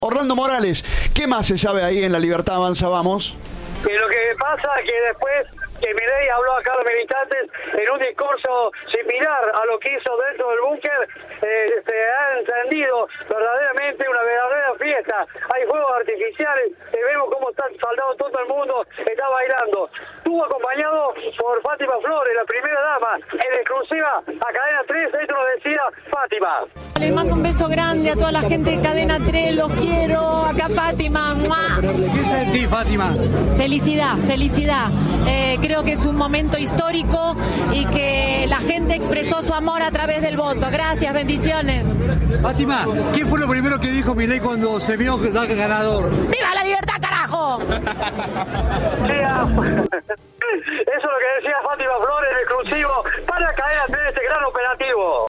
Entrevista de Viva la Radio.